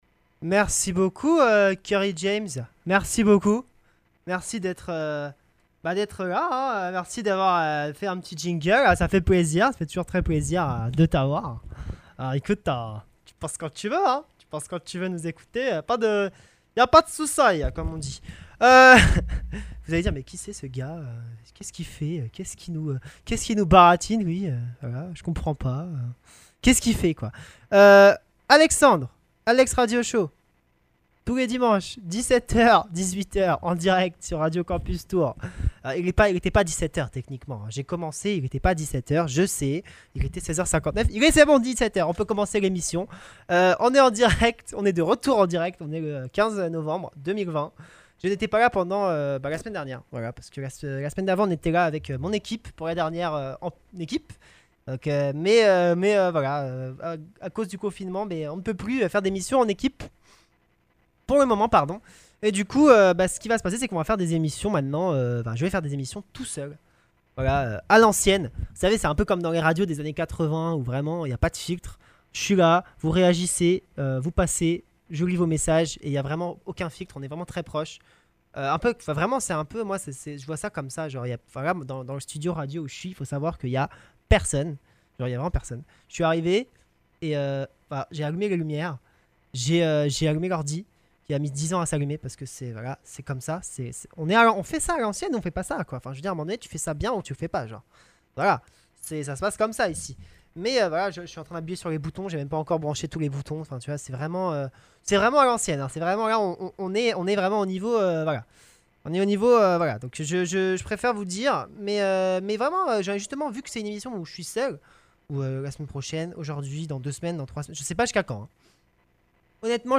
Des pauses musicales rythment le show toutes les 8 à 10 minutes environ !